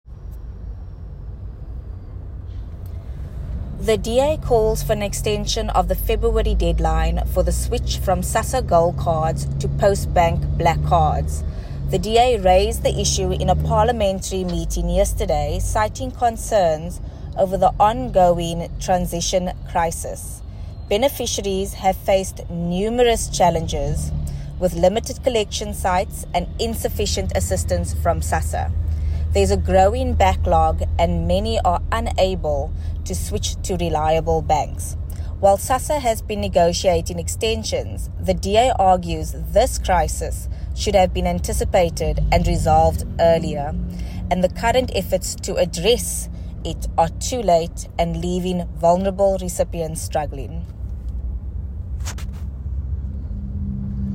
soundbite by Alexandra Abrahams MP.